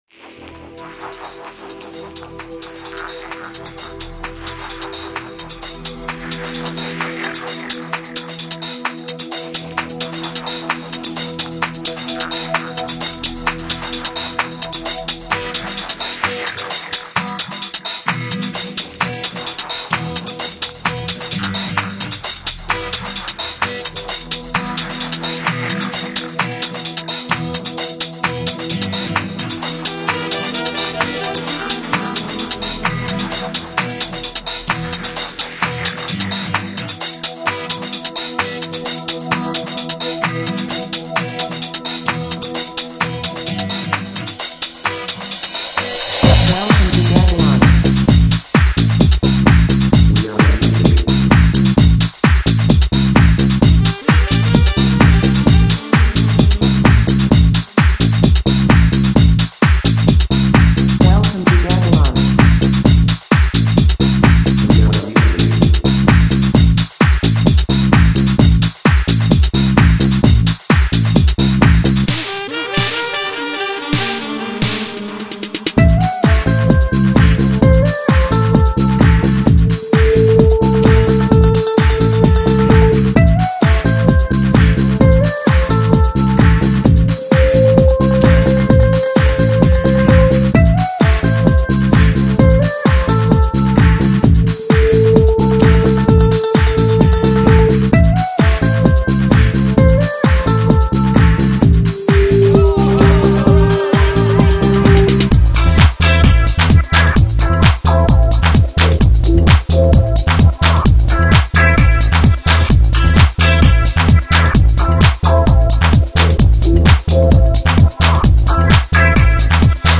dance/electronic
House
Leftfield/noise
Ambient